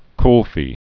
(klfē)